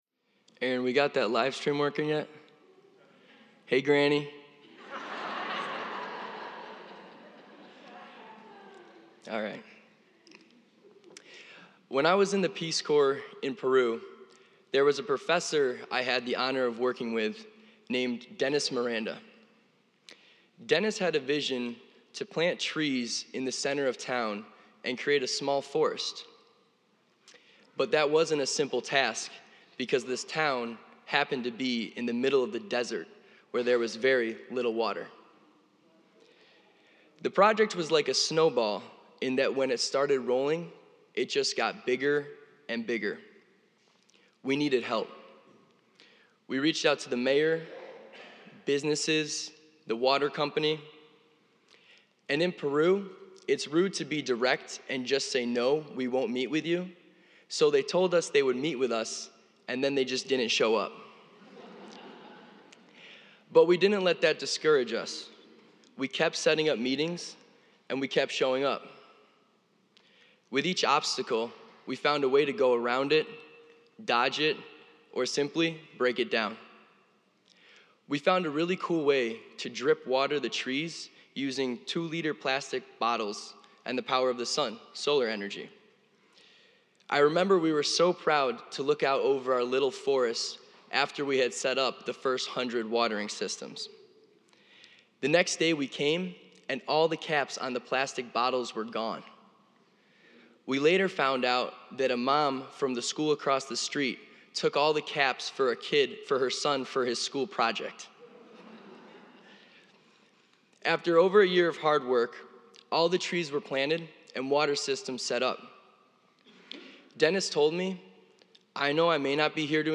preaches on the story of the widow and the unjust judge. The judge keeps refusing to give the widow a just decision, but like water on rock she wears down his stony heart. Through sheer persistence she is able to win.